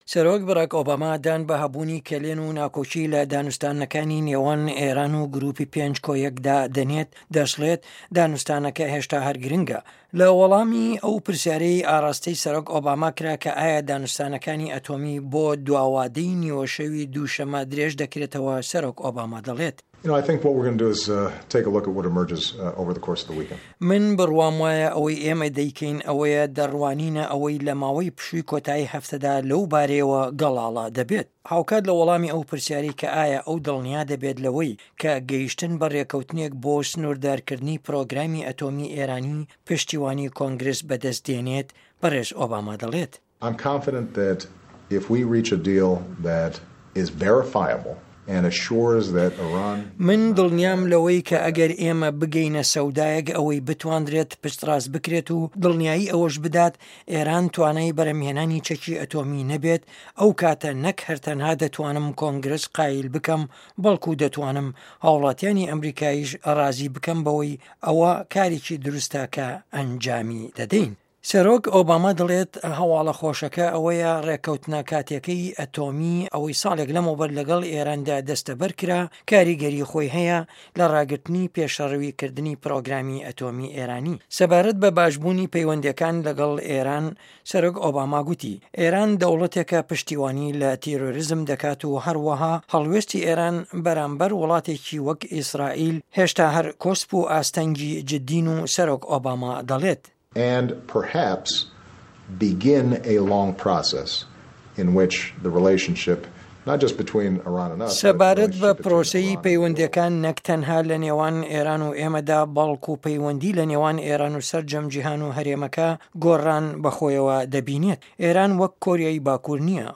ڕاپـۆرتی ئه‌مه‌ریکا - ئێران